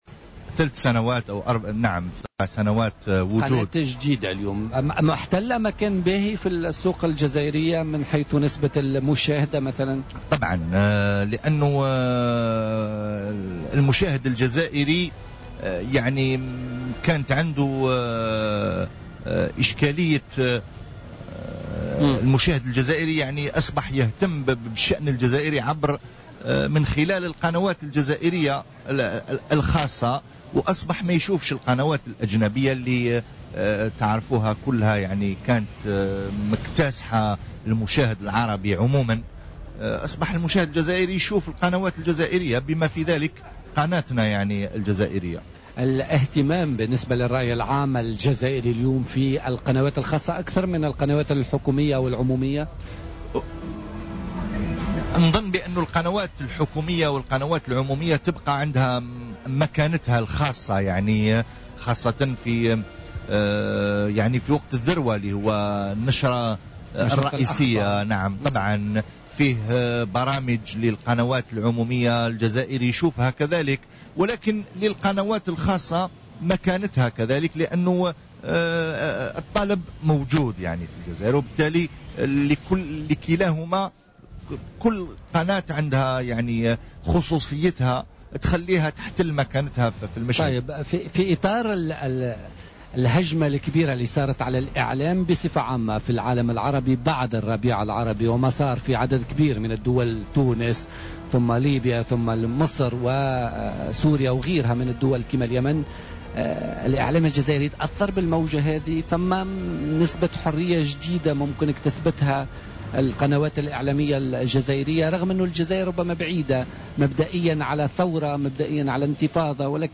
في تصريح للجوهرة أف أم
في مدخلة له من جناح الجوهرة أف أم في المهرجان العربي للإذاعة والتلفزيون بالحمامات